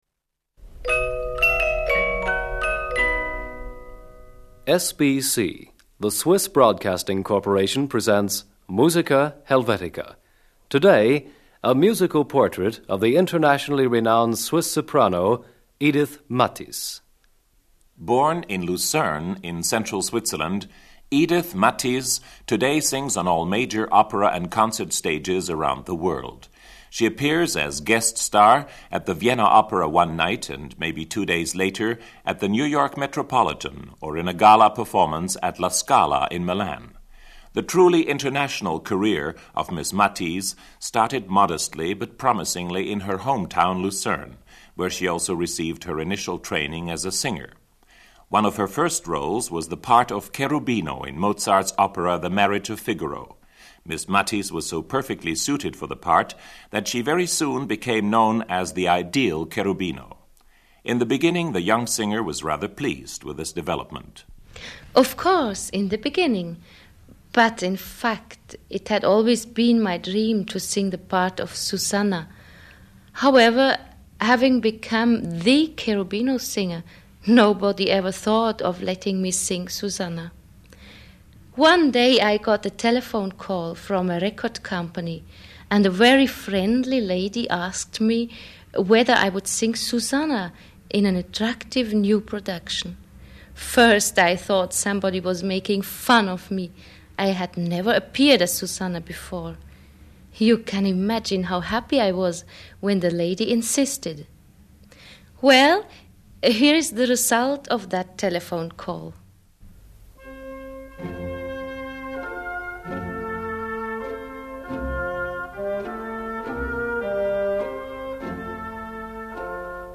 A musical portrait of internationally-renowned Swiss soprano Edith Mathis.
From “Die Hochzeit des Figaro”Edith Mathis, Soprano